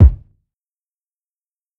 Hard Kick.wav